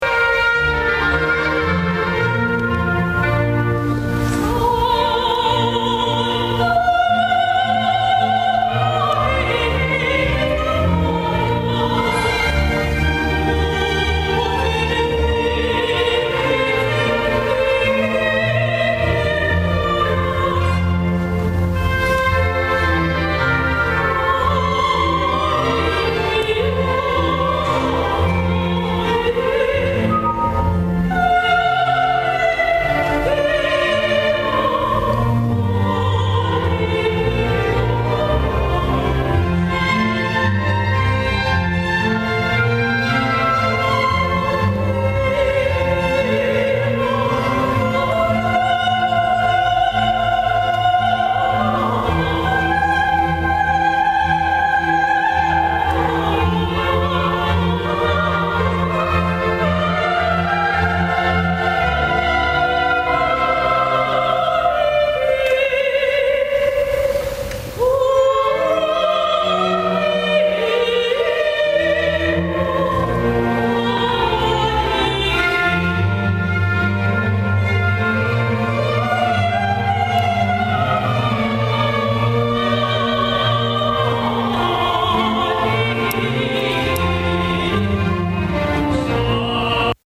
soprano solista
O interpretaciones de canto gregoriano, como la bellísima composición